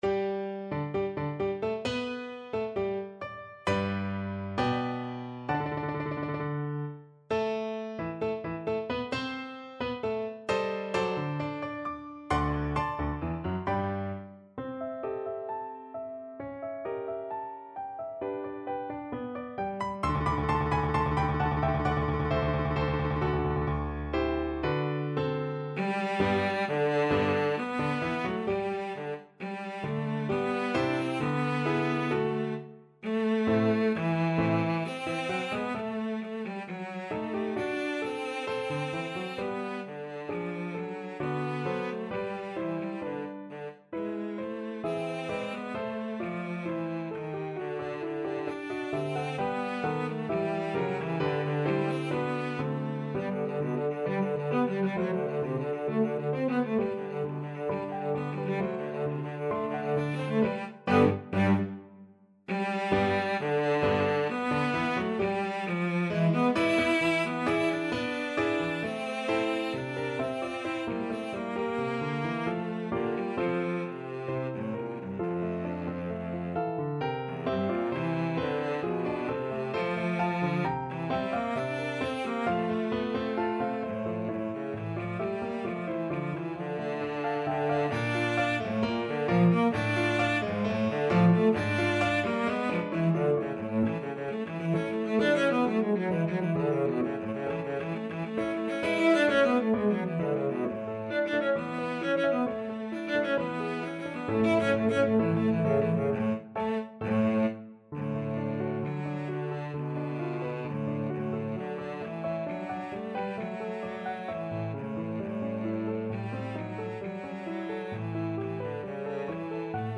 Free Sheet music for Cello
4/4 (View more 4/4 Music)
G major (Sounding Pitch) (View more G major Music for Cello )
~ = 100 Allegro moderato =132 (View more music marked Allegro)
Classical (View more Classical Cello Music)